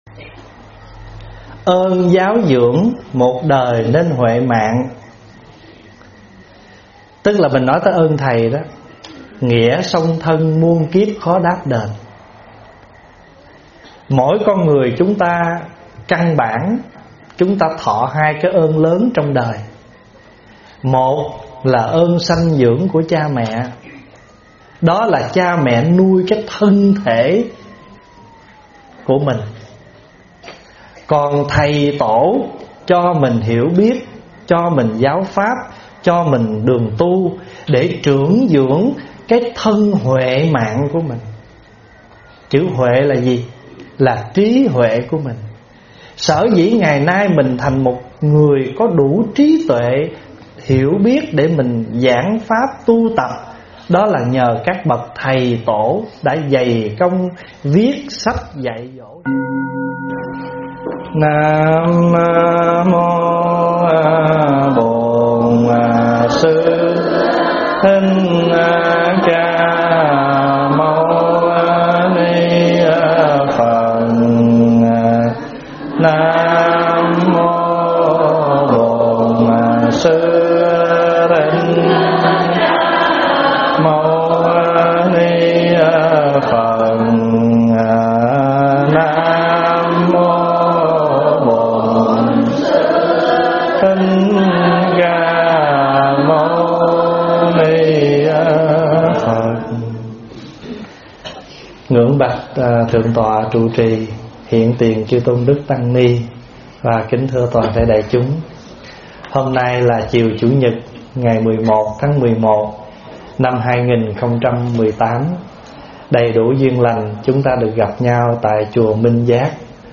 Đền Ơn Giáo Dưỡng ( Vấn Đáp